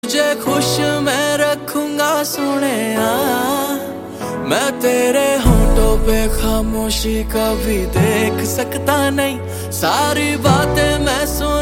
I have played music through aplay -Dplughw:1,0 -t orgsong.wav and getting playback in slow motion than the original sound.
I tried  'simple-audio-card,format = "dsp_b";' but Still I hear sound in slow motion with the same result as earlier.